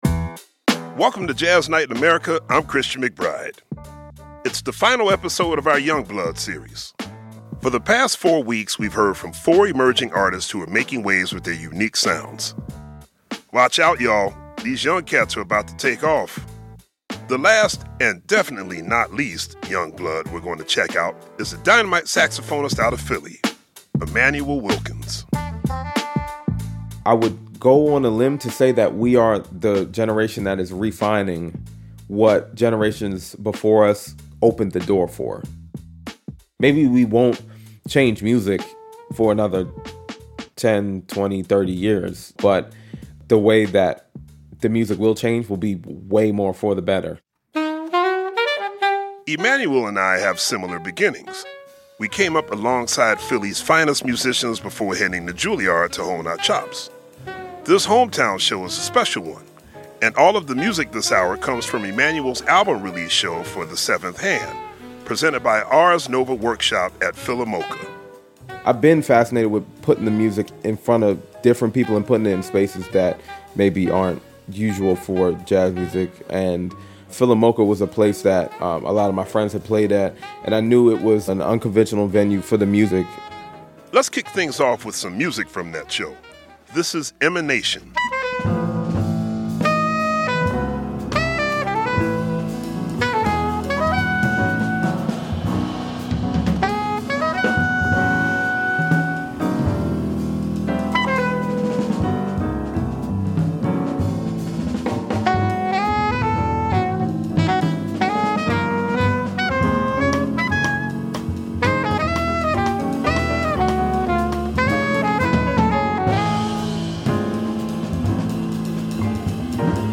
saxophonist and composer
jazz